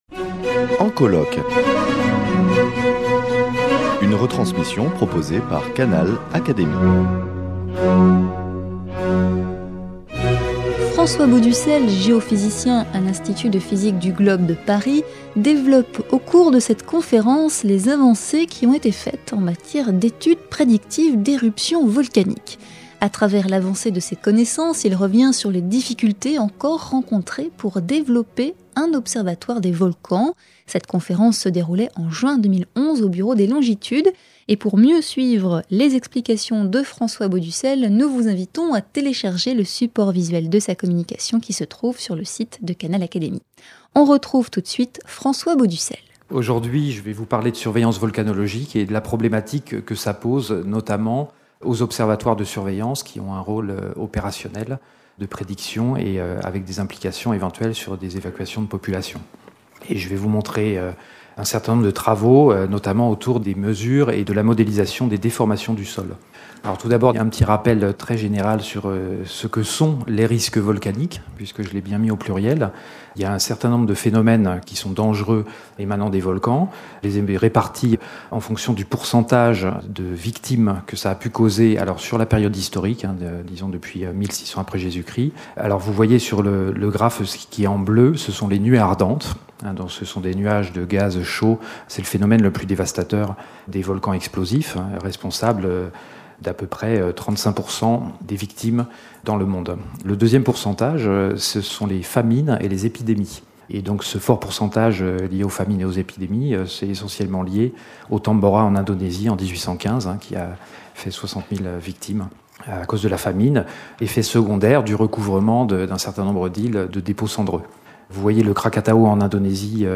Cette conférence se déroulait en juin 2011 au Bureau des longitudes.